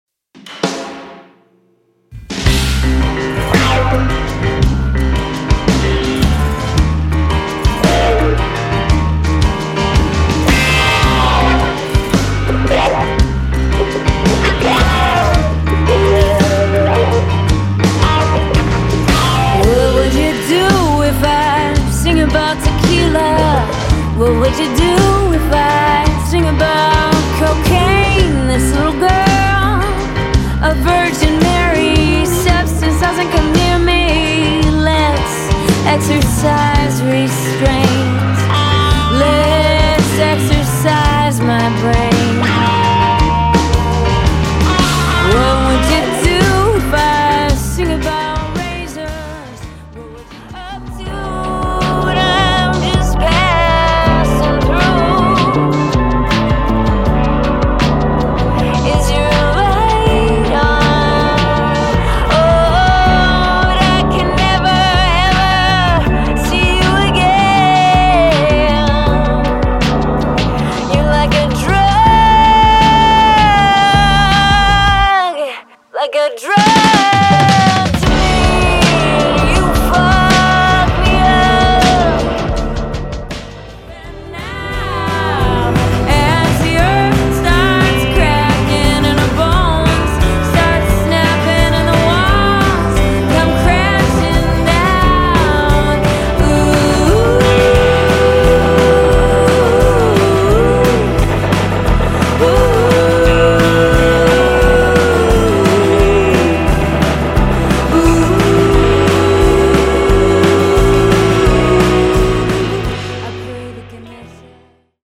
Drums, percussion, programming
poppy, emotionally open songs
plays piano in a definitively rhythmic style